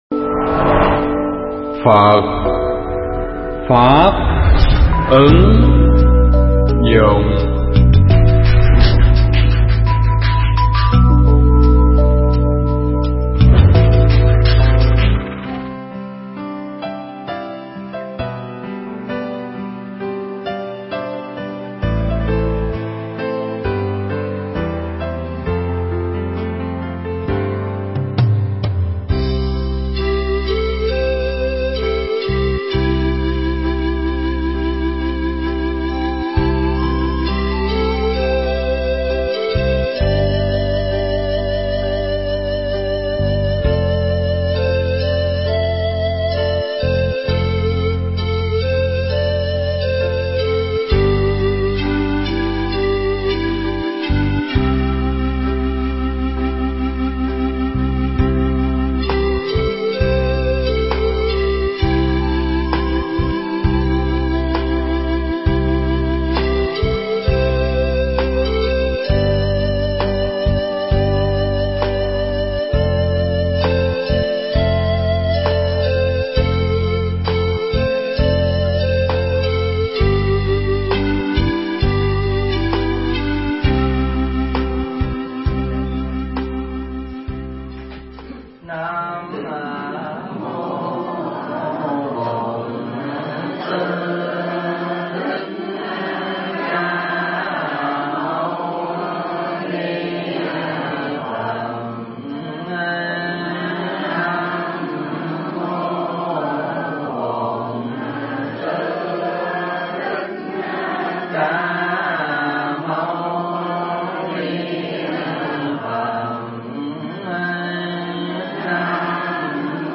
Bài giảng Kinh Bách Dụ